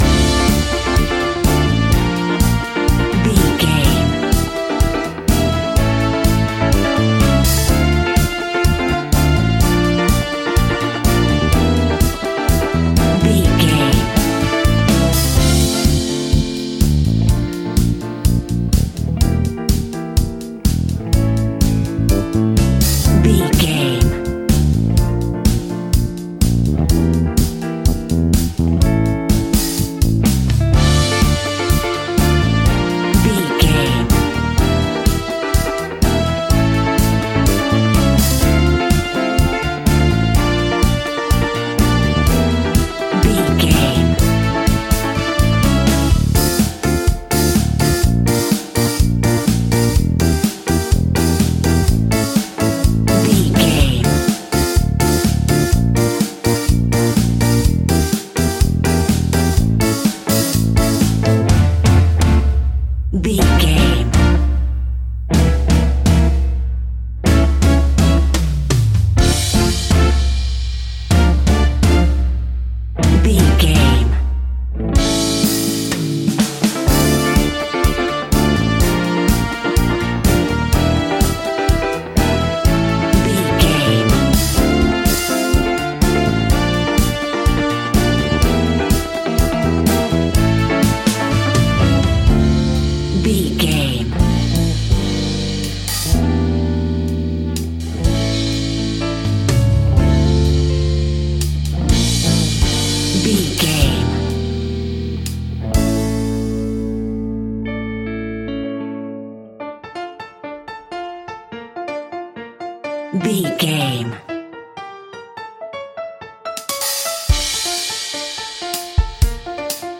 Aeolian/Minor
Fast
world beat
salsa
drums
bass guitar
electric guitar
piano
hammond organ
percussion